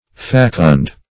Facund \Fac"und\, a.